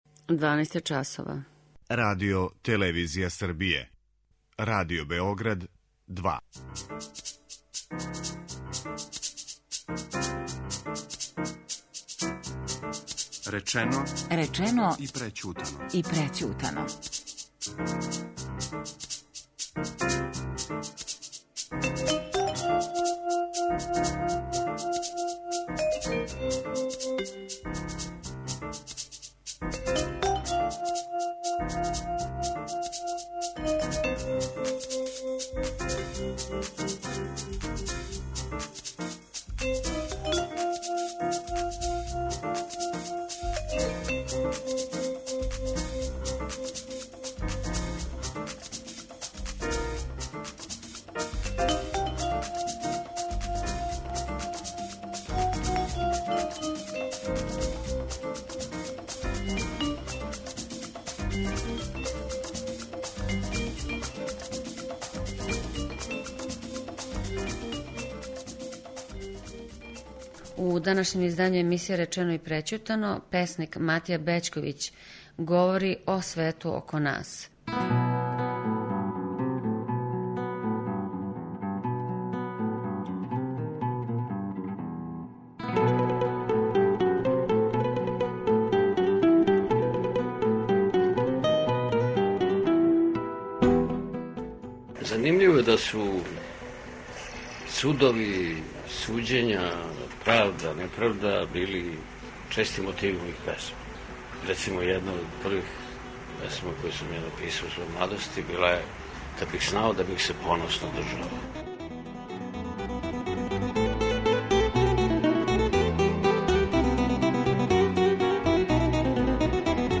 Песник Матија Бећковић казиваће своје стихове о лажи, истини и правди.